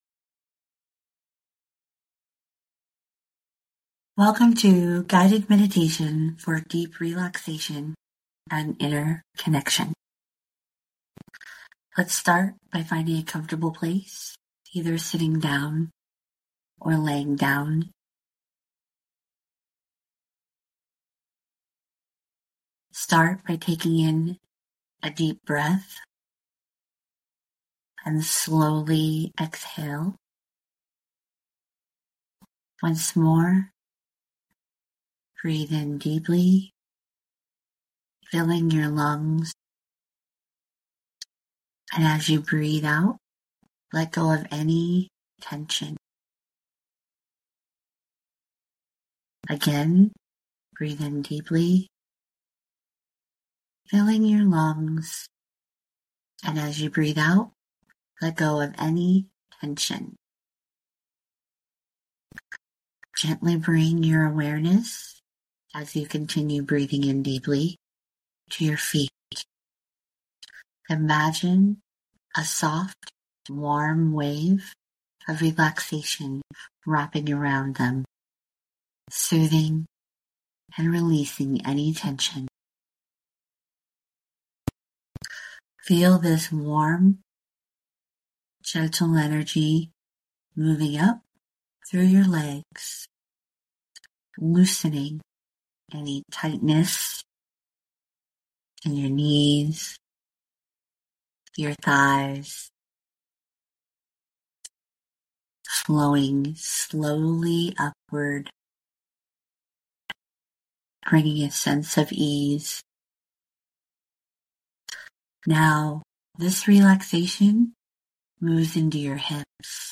Please feel free to click the photo and listen to this free meditation!